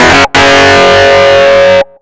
RIFFSYNT04-L.wav